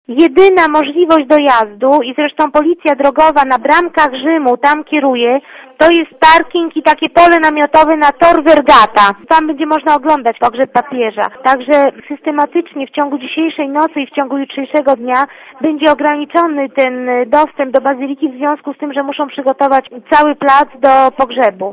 * Posłuchaj relacji pani konsul*